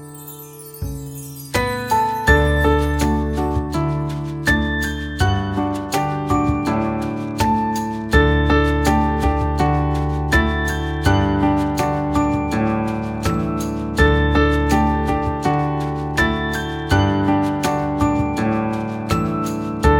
Boże Narodzenie